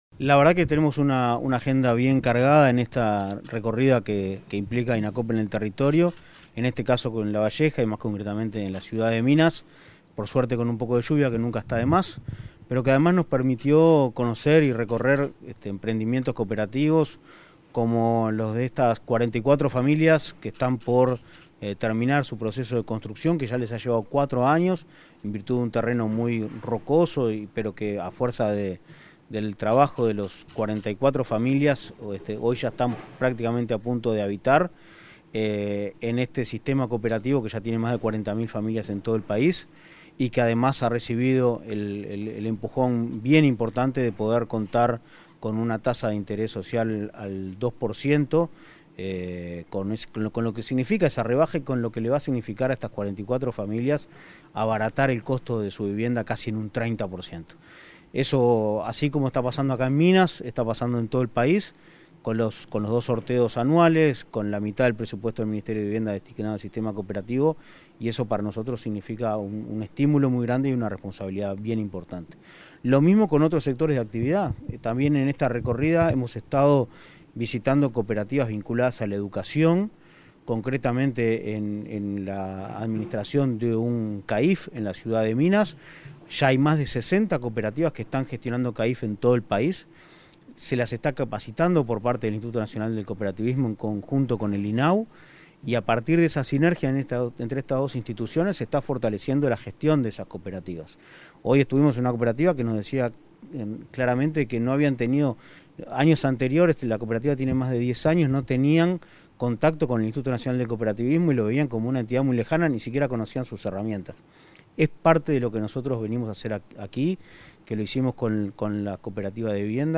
Declaraciones del presidente del Inacoop, Martín Fernández